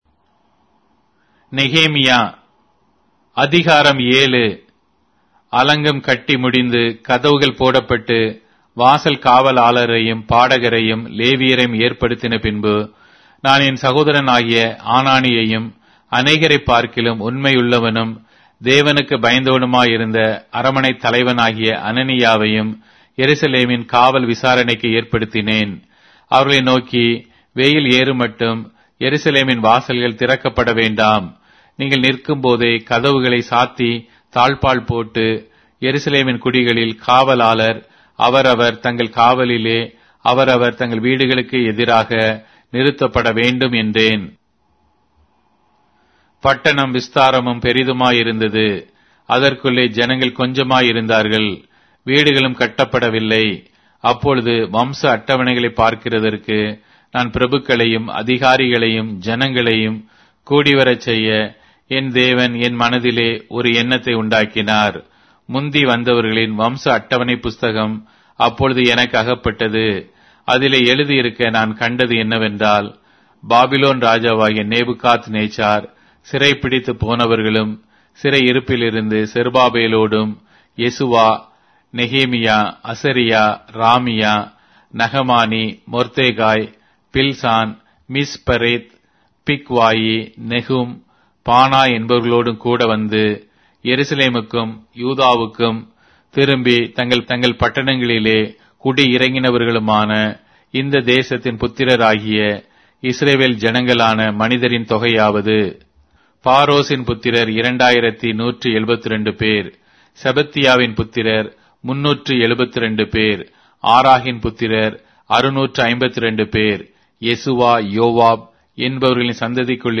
Tamil Audio Bible - Nehemiah 2 in Ocvbn bible version